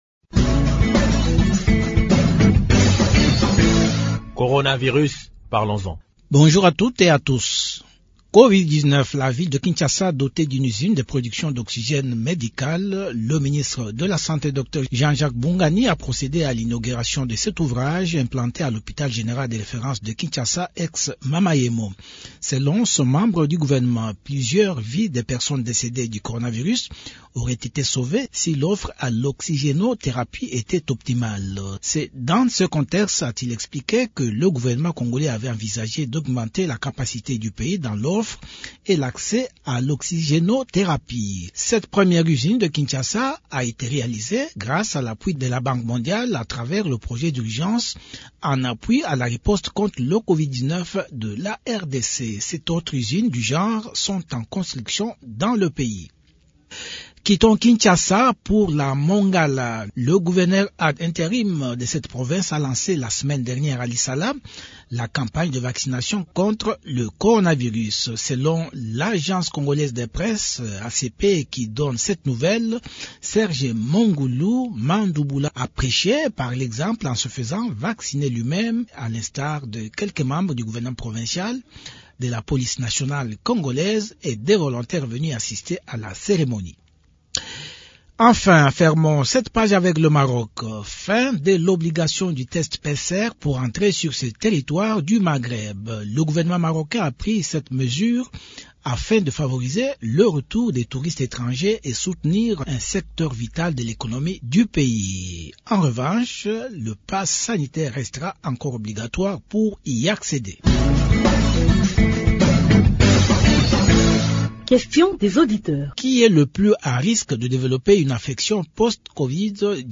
au micro d’une chaine locale de l’audio-visuel.